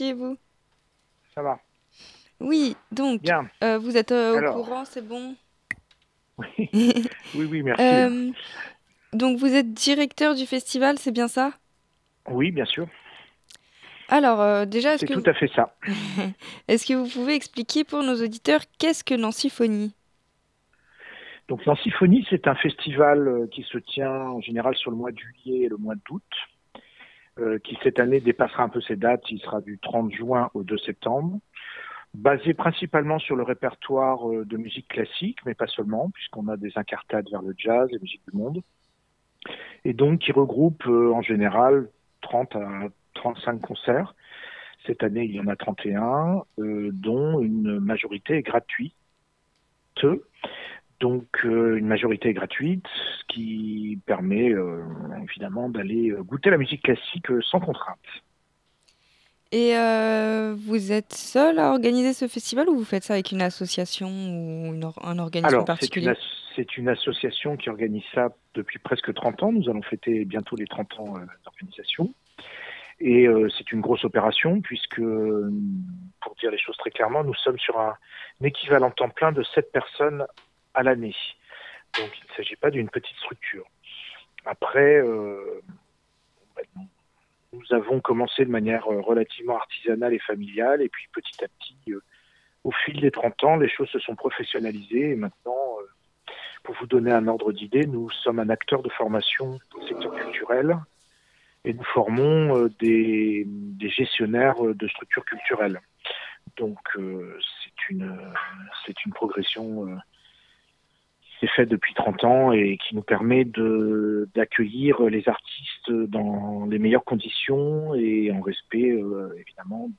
itw-nancyphonies.mp3